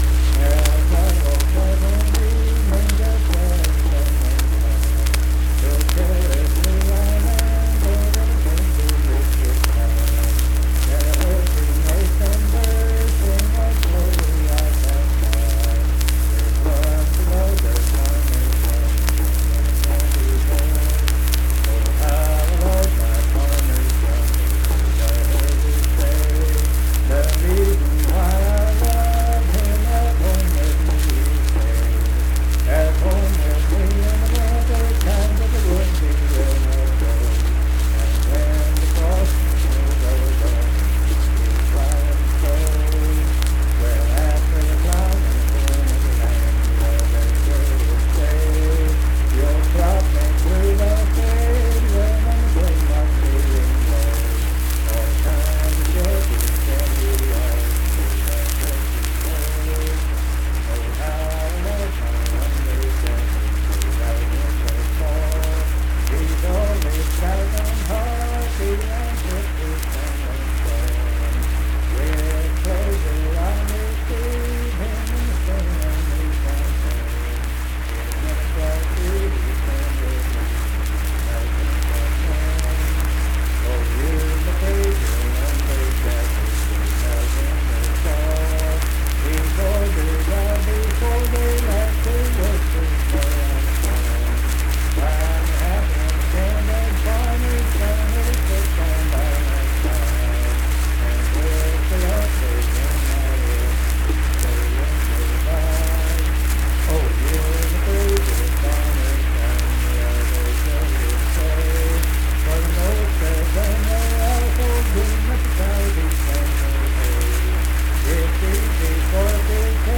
Unaccompanied vocal music
Performed in Kanawha Head, Upshur County, WV.
Voice (sung)